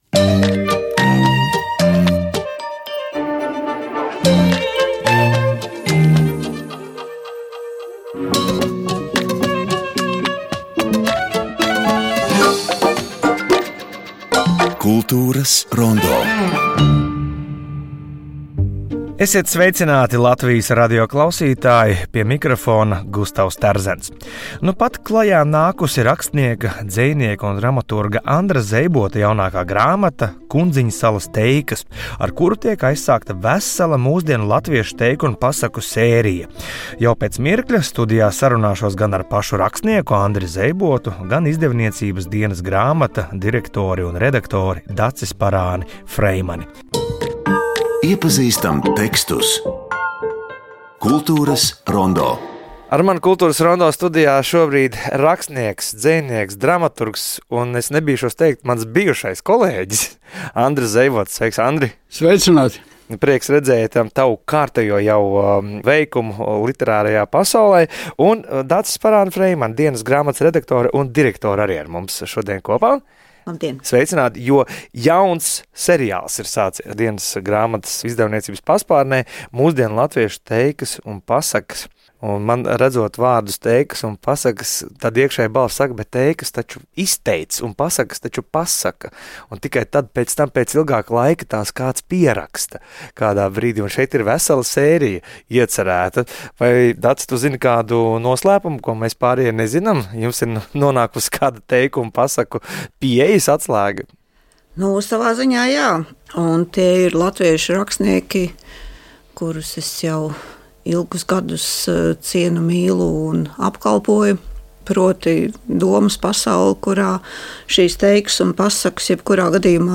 "Kultūras rondo" ir kvalitatīvākais un daudzpusīgākais radio raidījums par kultūras procesiem Latvijā un pasaulē, kas sniedz arī izvērstas anotācijas par aktuāliem notikumiem mūzikā, mākslā, kino, teātrī, literatūrā, arhitektūrā, dizainā u.c. "Kultūras rondo" redzes lokā ir tā kultūrtelpa, kurā pašreiz dzīvojam. Mēs ne tikai palīdzam orientēties kultūras notikumos, bet tiešraides sarunās apspriežam kultūras notikumu un kultūras personību rosinātas idejas.